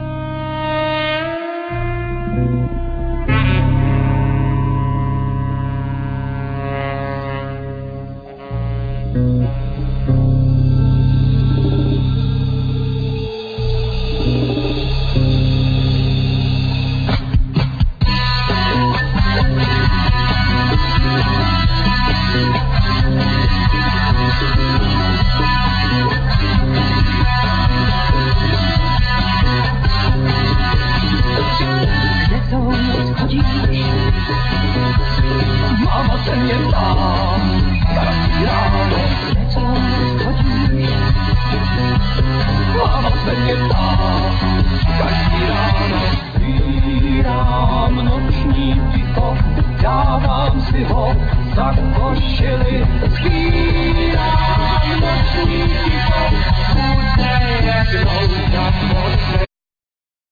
Vocals,Guitar
Bass,Vocals
Drums
Percussions
Tennor saxophone,Vocals